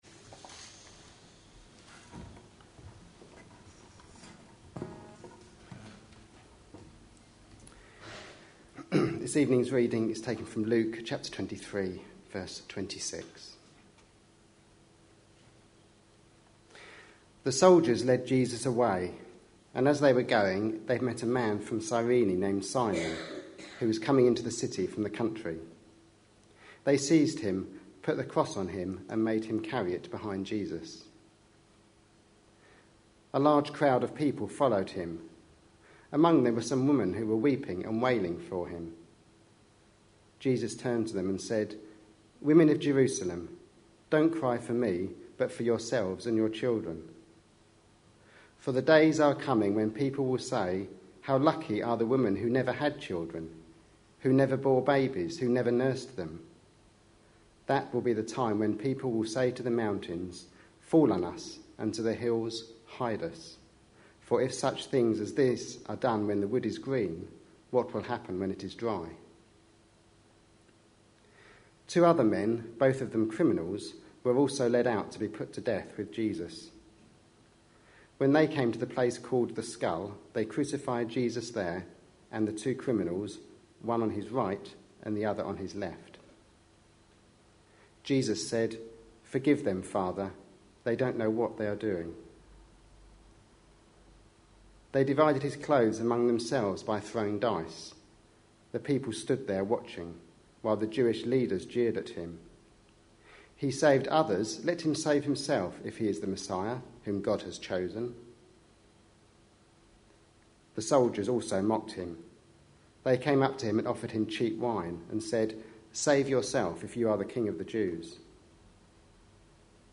A sermon preached on 24th March, 2013, as part of our Passion Profiles and Places -- Lent 2013. series.